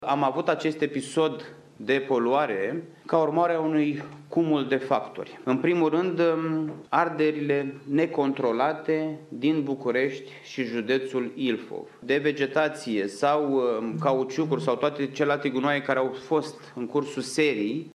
Ministrul Mediului a explicat că în cursul nopţii, anumite firme din Bucureşti şi Ilfov desfăşoară activităţi industriale care poluează: